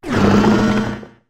clodsire_ambient.ogg